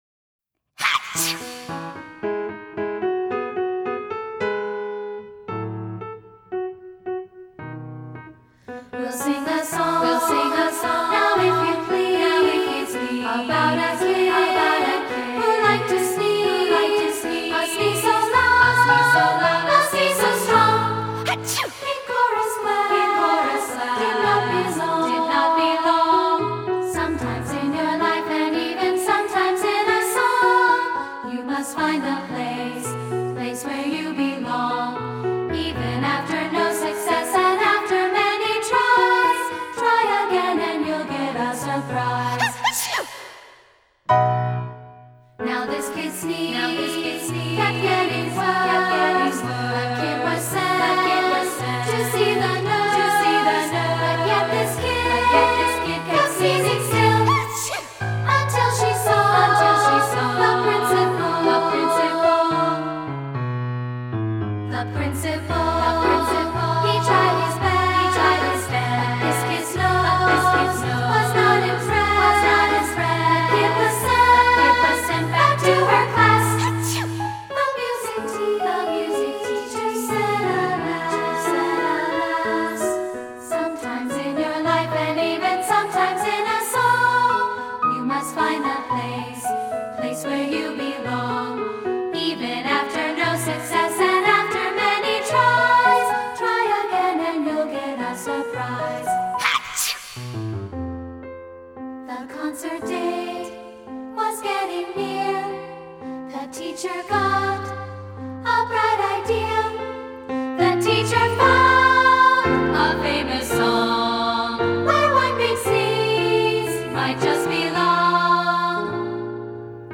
two-part treble voices & piano